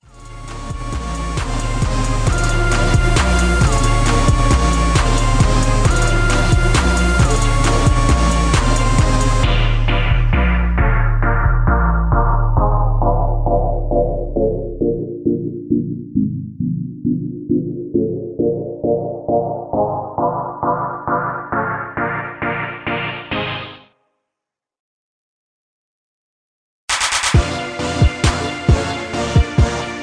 Anthem Type